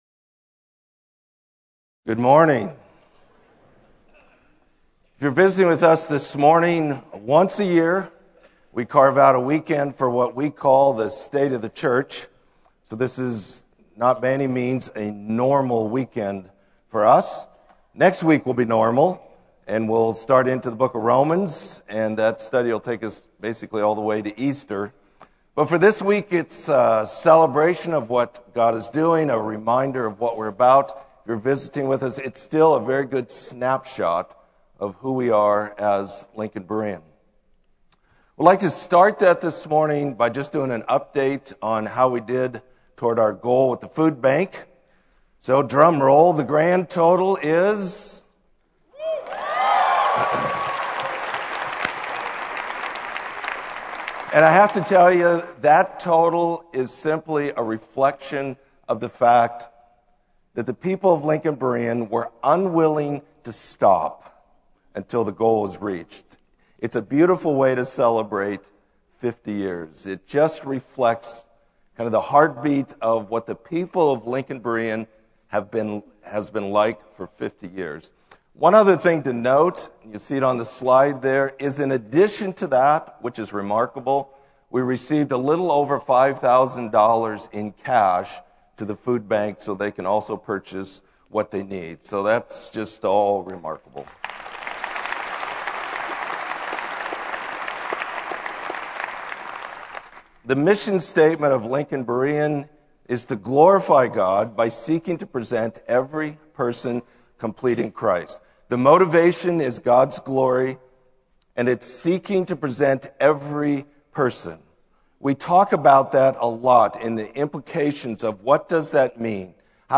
Sermon: State of the Church 2012